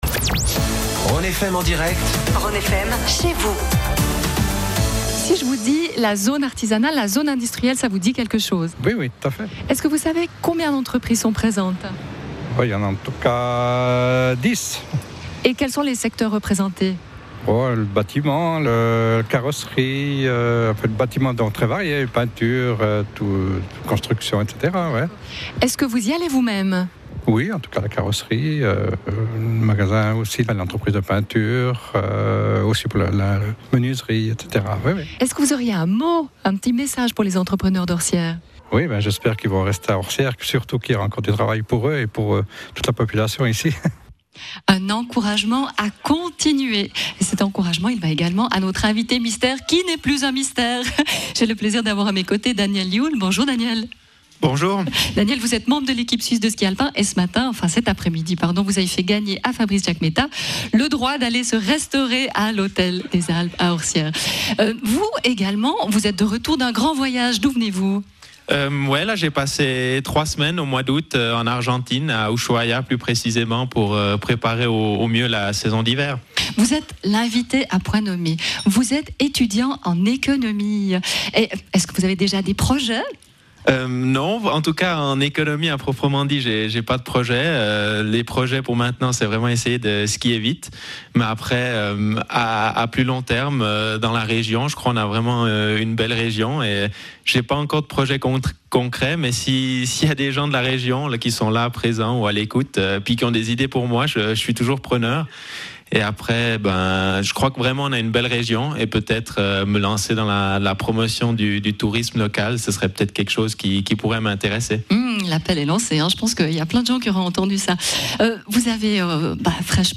Interview de l'invité mystère, M.
Daniel Yule, skieur alpin suisse professionnel